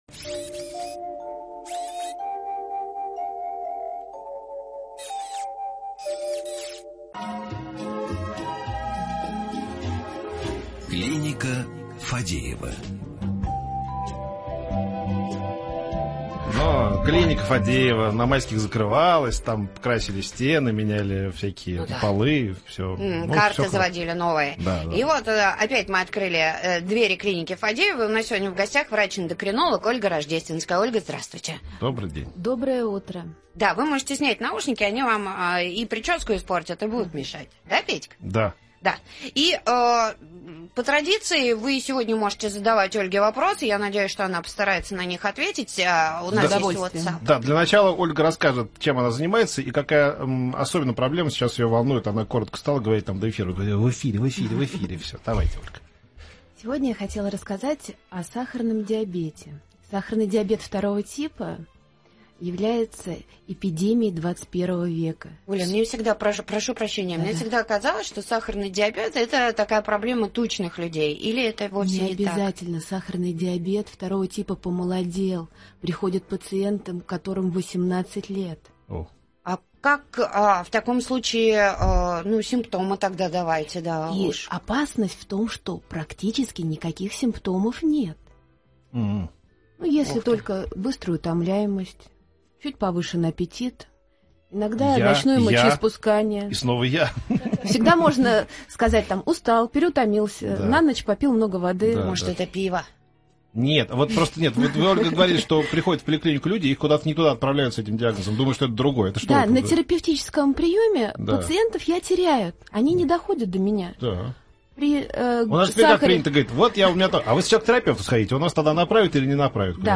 Источник: Радио Маяк